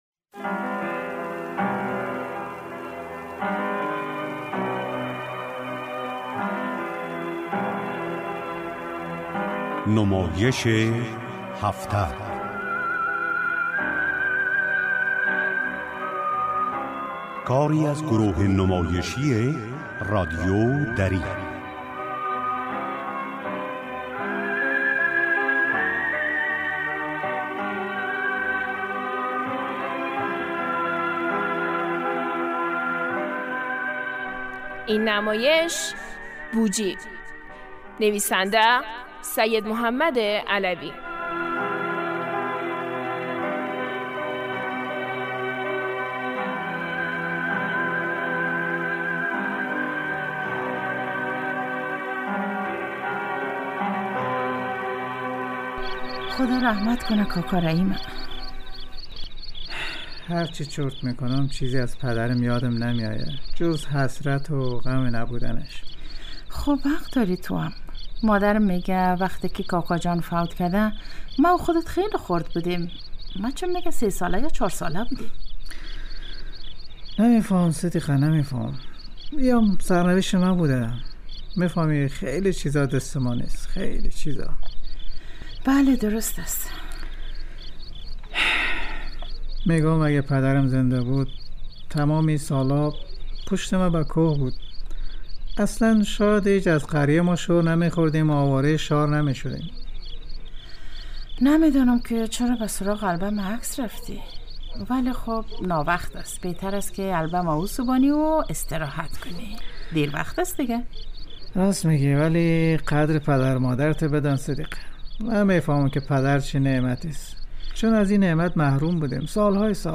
نمایش هفته